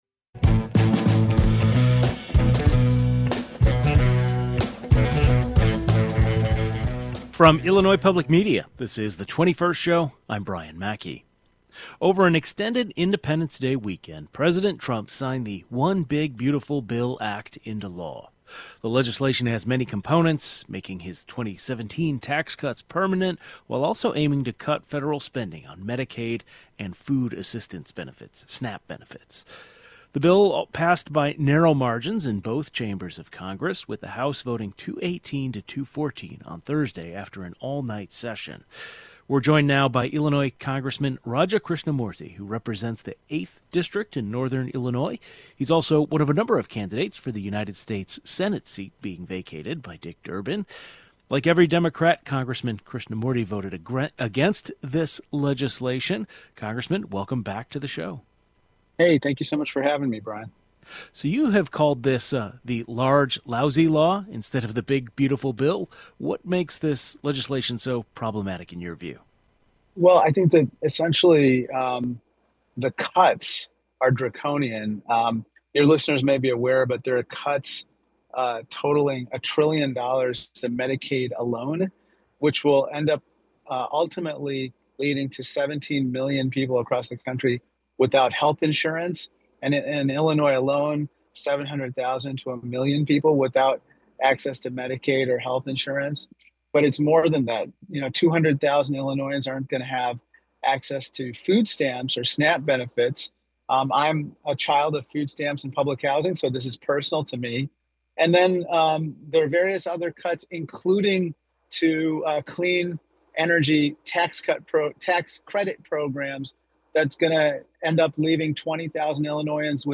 Like every Democrat, Congressman Raja Krishnamoorthi voted against the "Big Beautiful Bill" Act and he joins the program to explain why.